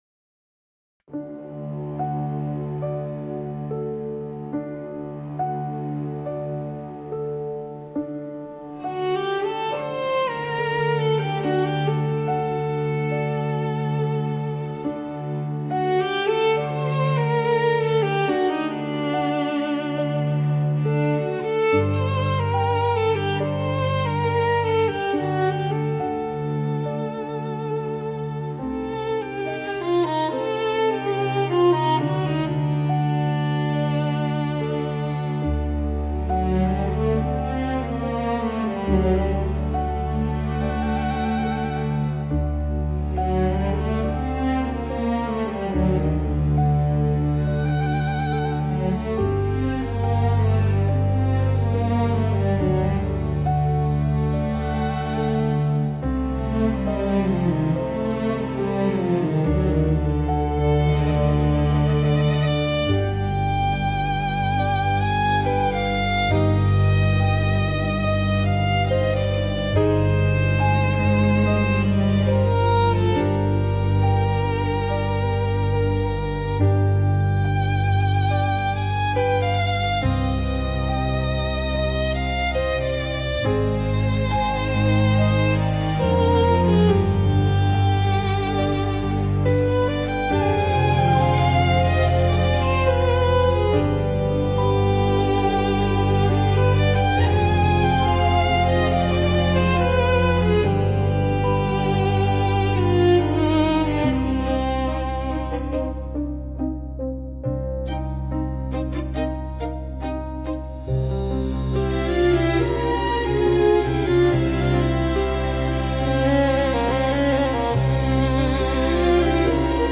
remixed orchestral arrangement